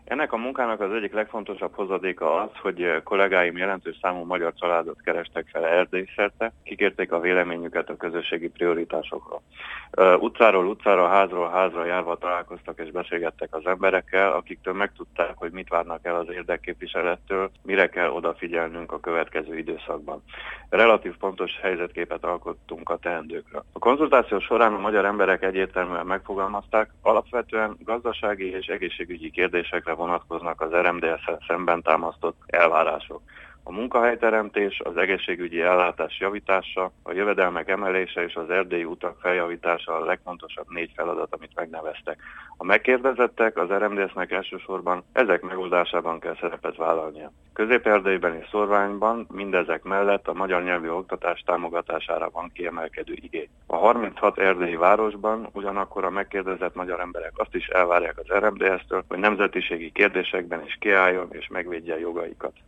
nyilatkozatát